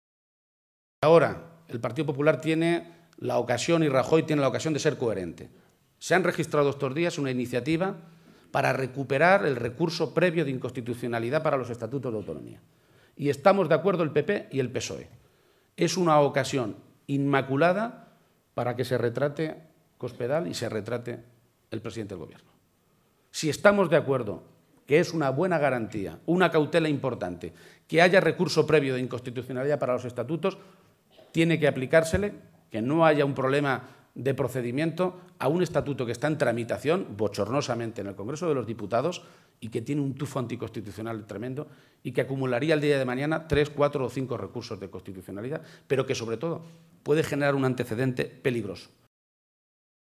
García-Page se pronunciaba de esta manera durante el ciclo de conferencias del Foro Nueva Economía, en Madrid, y en el que ha sido presentado por la Presidenta de Andalucía, Susana Díaz, que ha dicho del líder socialista castellano-manchego que es “un buen político, un buen socialista y un buen alcalde”.